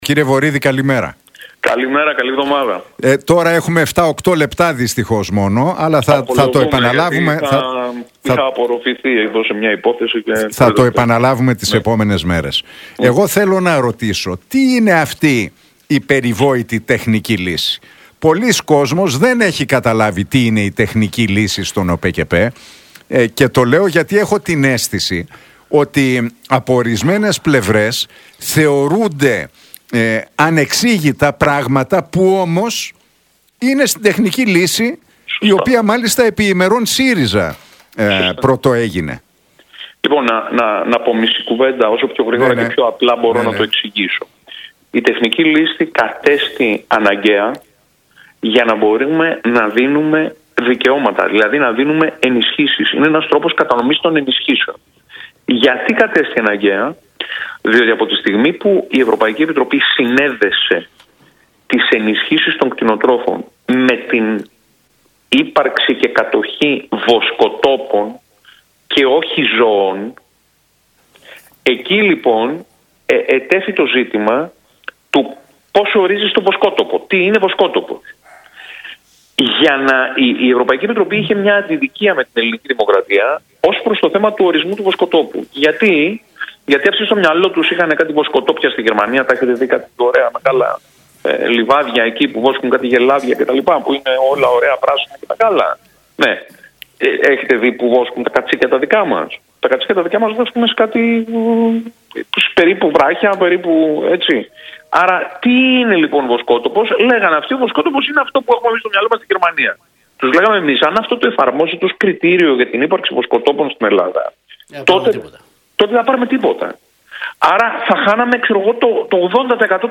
Για την υπόθεση του ΟΠΕΚΕΠΕ και την τεχνική λύση μίλησε ο βουλευτής της ΝΔ, Μάκης Βορίδης, μιλώντας στον Realfm 97,8